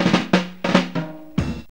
FILL 1    98.wav